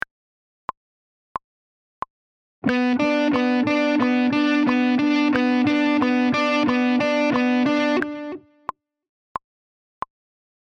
Example 1 – Pick and Second Finger
The first example shows a basic hybrid-picking pattern using notes of the B Minor Pentatonic scale. This example alternates between using a down-pick on the D string and second-finger pick on the G string.